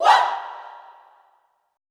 Index of /90_sSampleCDs/Best Service - Extended Classical Choir/Partition I/FEM SHOUTS
FEM OOAH  -L.wav